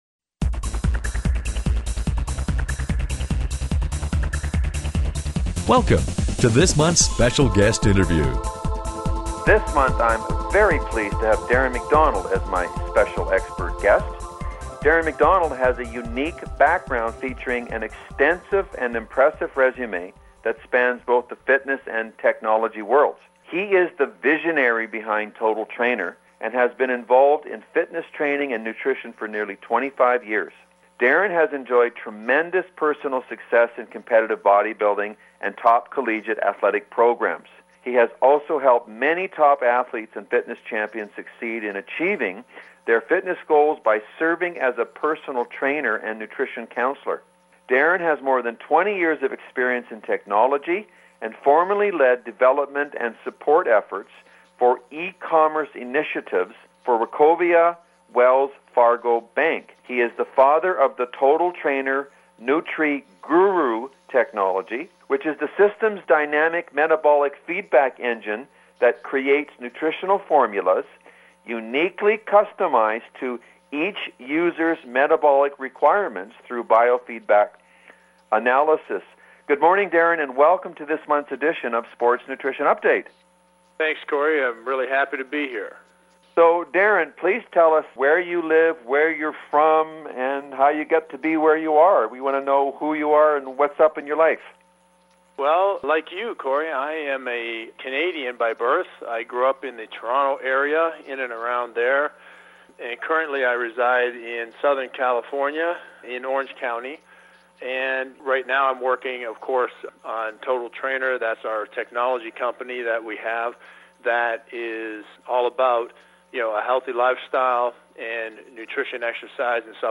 Special Guest Interview Volume 11 Number 10 V11N10c